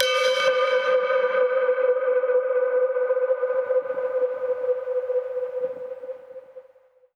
Index of /musicradar/dub-percussion-samples/134bpm
DPFX_PercHit_A_134-02.wav